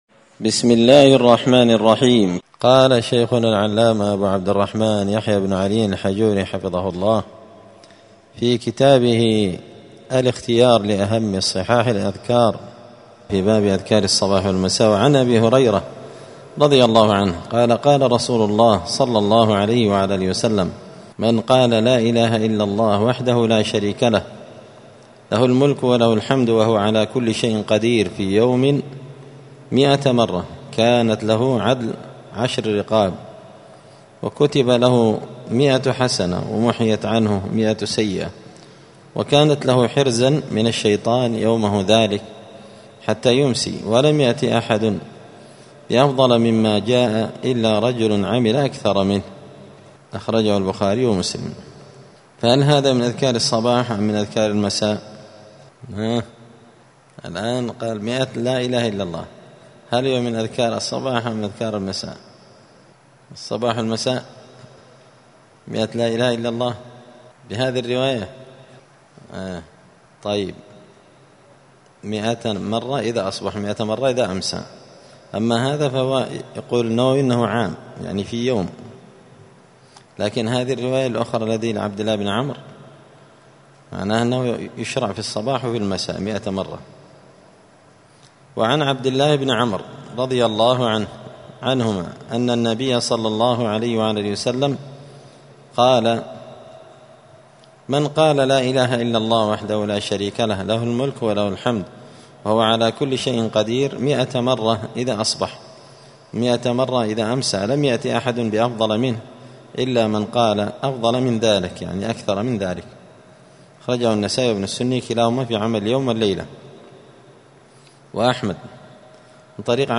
*{الدرس العاشر (10) الحديث الرابع من أذكار الصباح والمساء}*